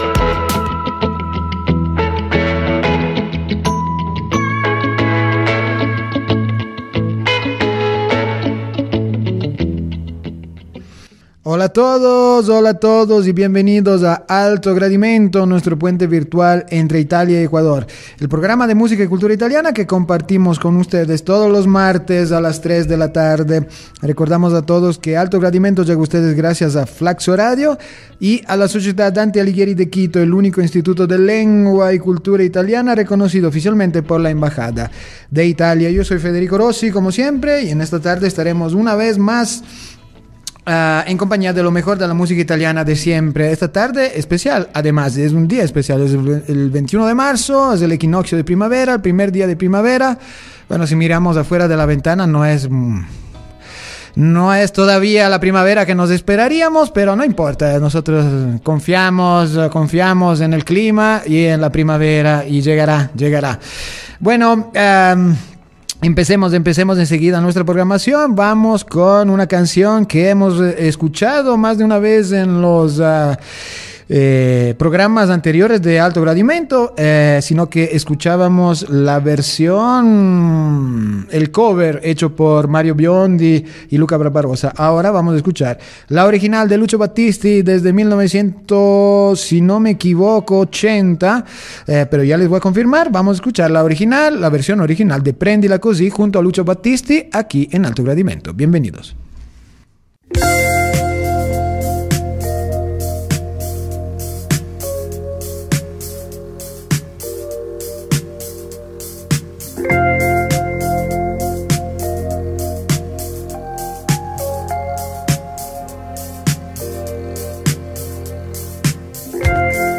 los grandes exponentes de la canción de autor italiana